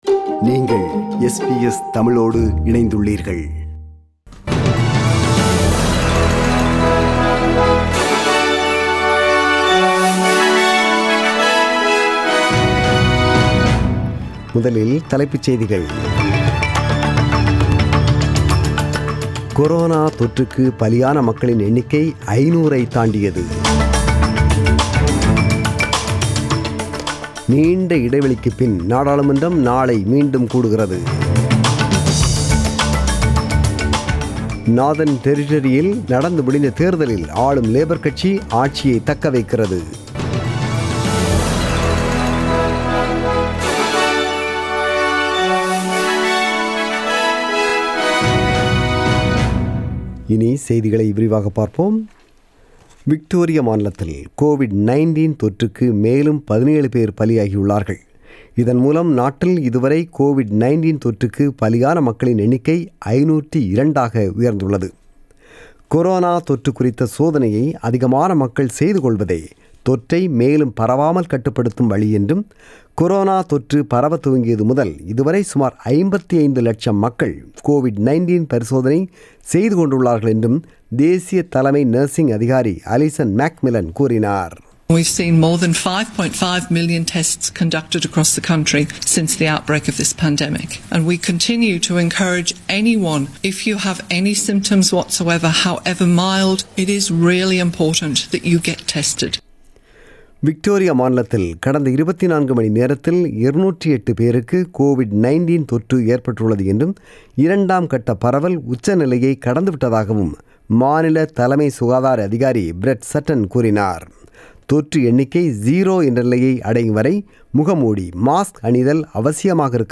The news bulletin was broadcasted on 23 August 2020 (Sunday) at 8pm.